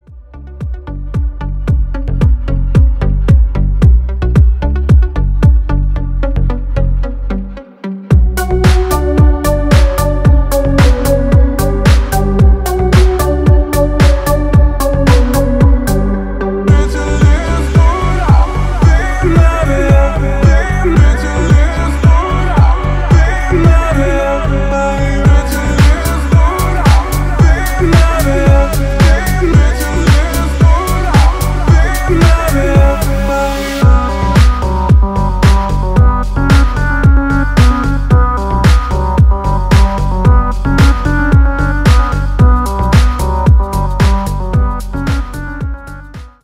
мужской голос
deep house
атмосферные
Electronic
спокойные
танцевальные
Стиль: deep house.